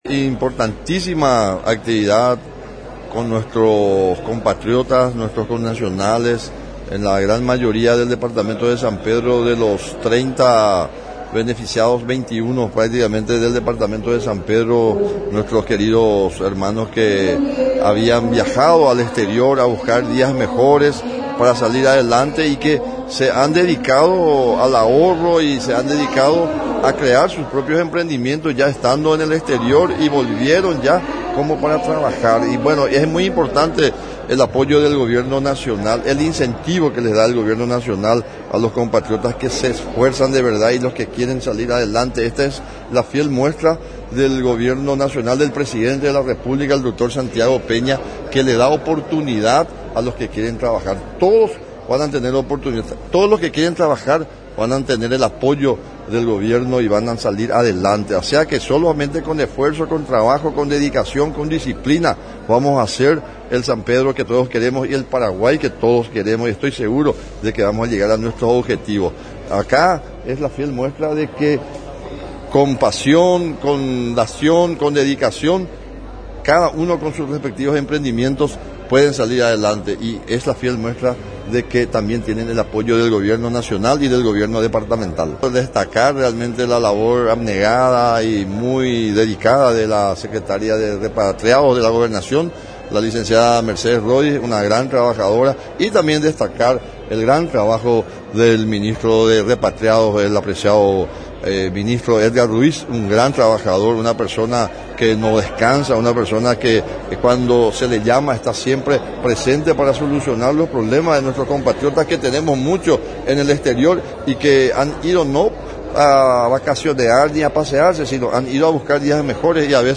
La actividad se realizó en el salón auditorio de la Gobernación de San Pedro.
Nota: Freddy Decclesiis-Gobernador de San Pedro
FREDDY-DECCLESIIS-GOBERNADOR-1.mp3